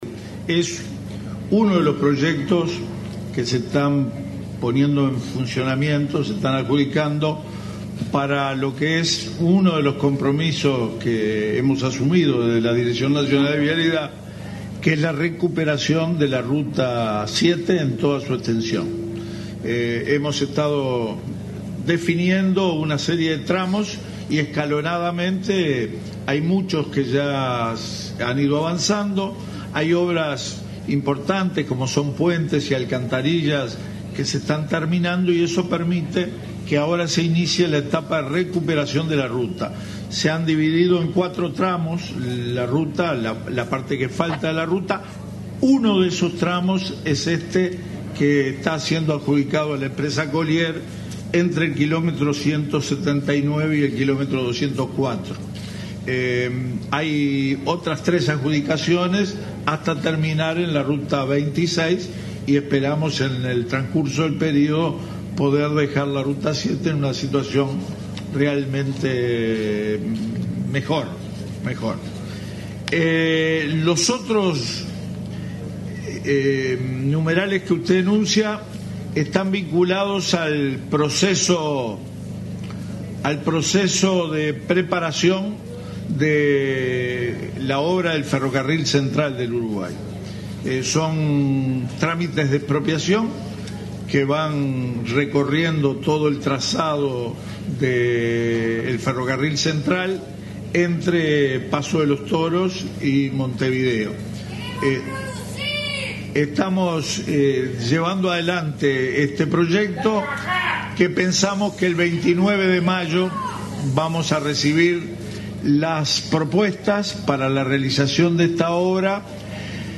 El ministro de Transporte y Obras Públicas, Víctor Rossi, confirmó en el Consejo de Ministros abierto de San José, que las obras para la construcción de la rotonda de ingreso a Playa Pascual se iniciarán en el segundo semestre de este año. También fue adjudicada la licitación para la recuperación de la ruta 7 en cuatro tramos. En otro orden, habló de la preparación para las obras del Ferrocarril Central.